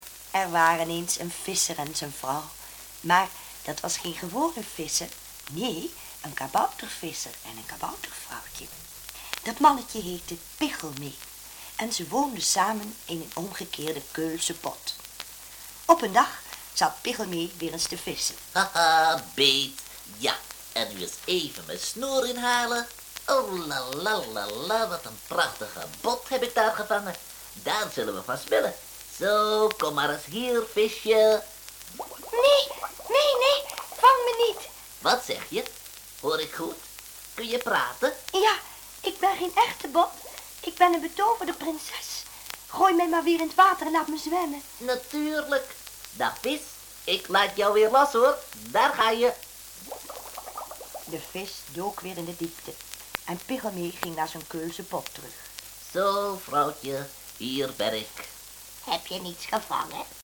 Sprookjes